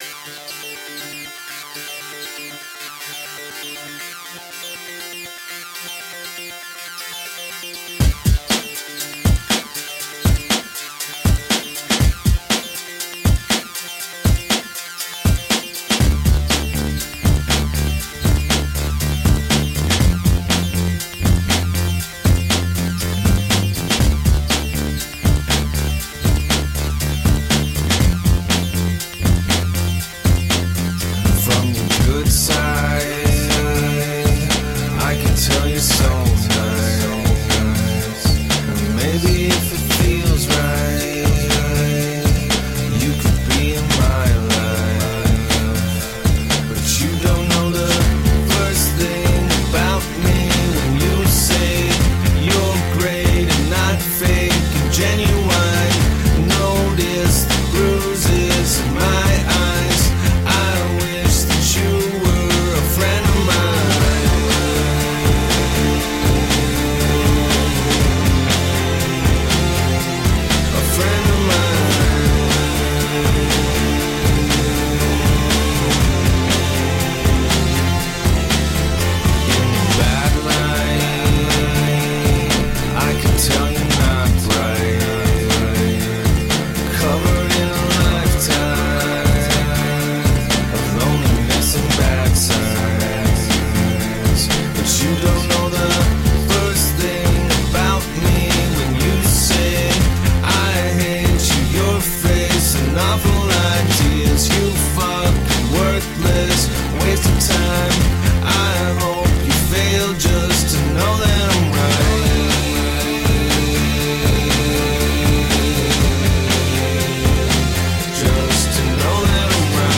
Brilliantly sardonic indie rock.
Tagged as: Alt Rock, Funk, Indie Rock